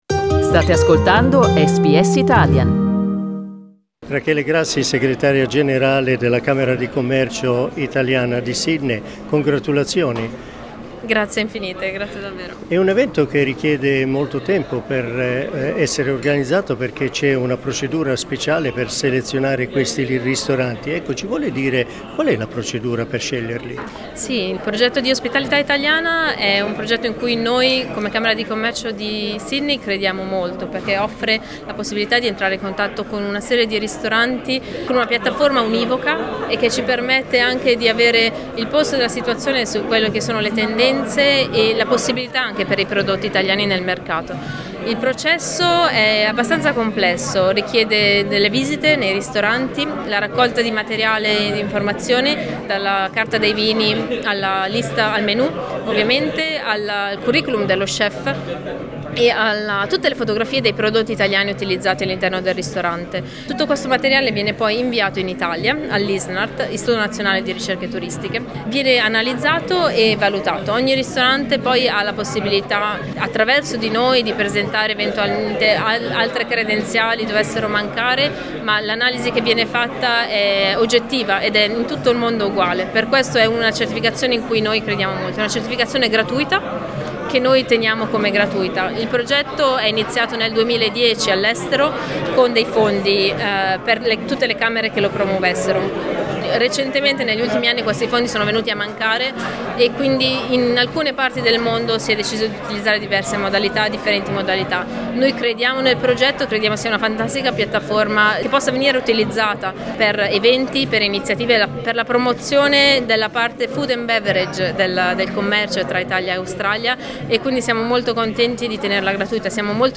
As part of the events linked to the fourth Week of Italian Cuisine in the World, the official opening gala was held in Sydney on Monday night. It included the awarding of Italian restaurants and chefs who stood out in the year that is about to end.